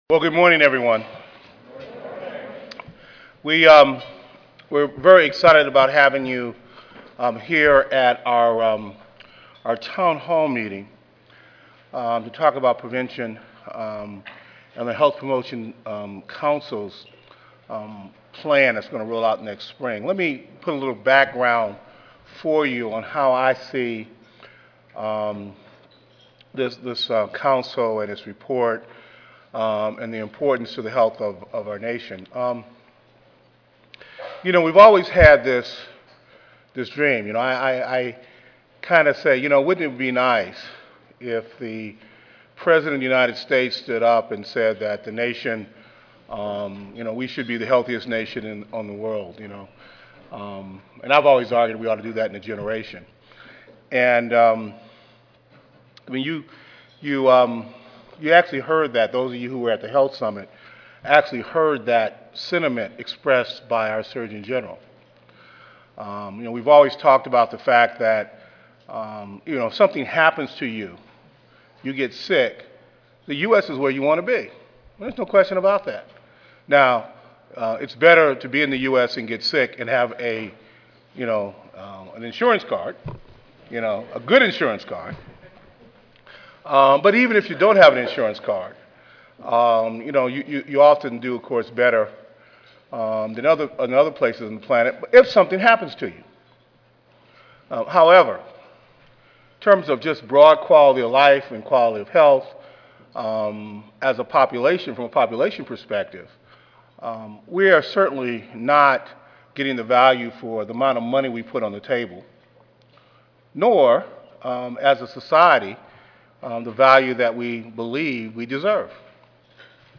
3000.2 National Prevention and Health Promotion Strategy Listening Session Monday, November 8, 2010: 7:30 AM - 9:30 AM Oral On March 23, 2009, President Obama signed the Patient Protection and Affordable Care Act, enacting comprehensive health reform legislation.